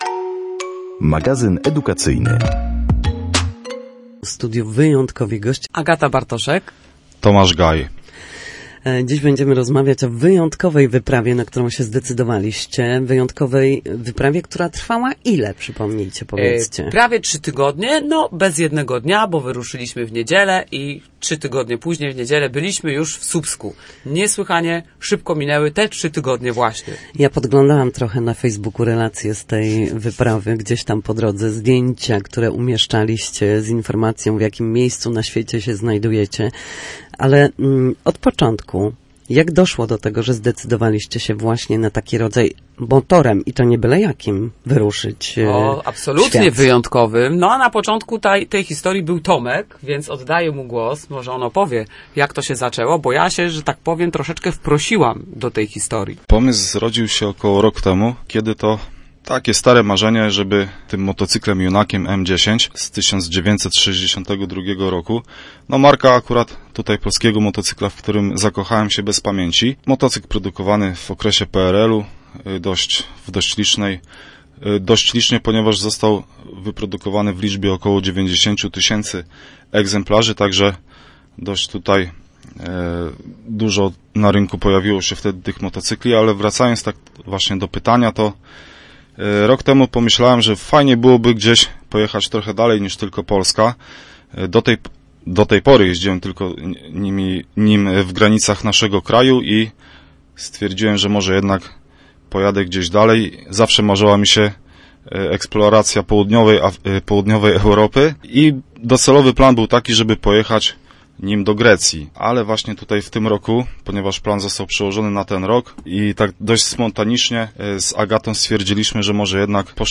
O swojej wycieczce opowiedzieli na naszej antenie.